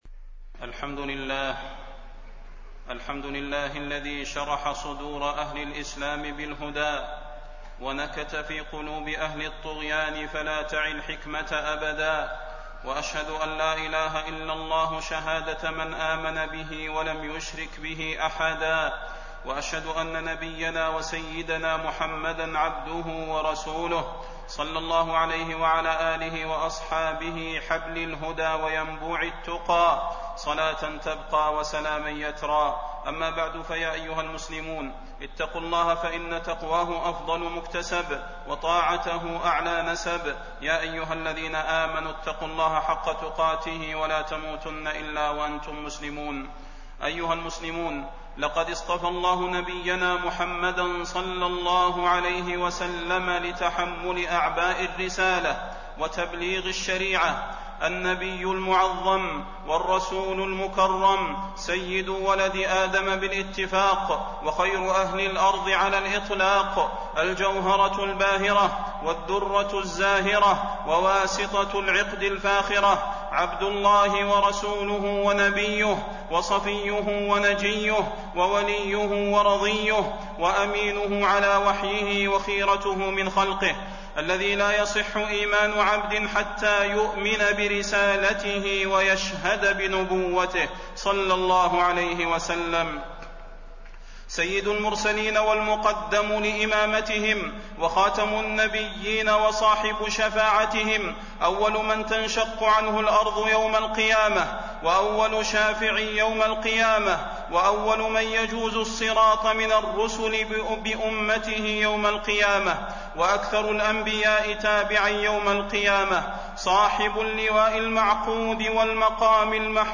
تاريخ النشر ٧ ذو القعدة ١٤٣١ هـ المكان: المسجد النبوي الشيخ: فضيلة الشيخ د. صلاح بن محمد البدير فضيلة الشيخ د. صلاح بن محمد البدير فضل الصلاة على النبي صلى الله عليه وسلم